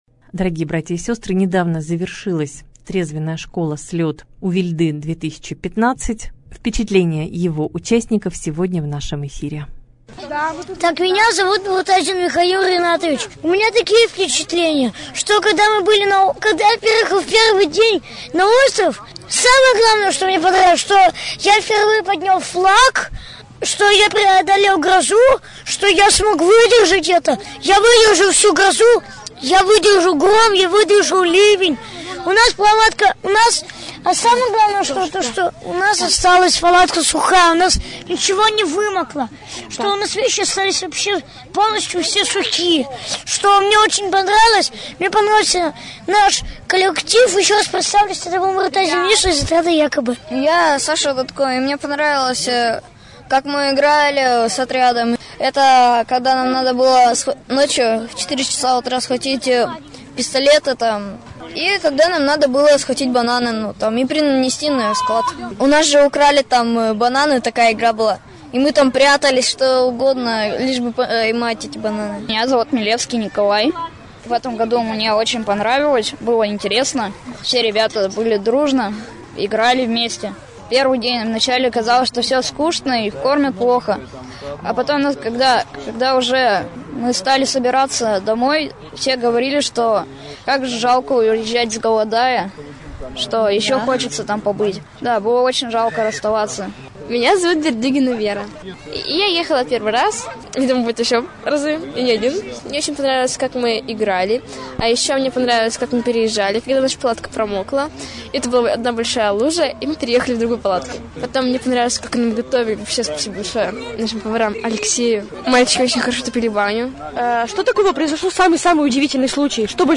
Репортаж дня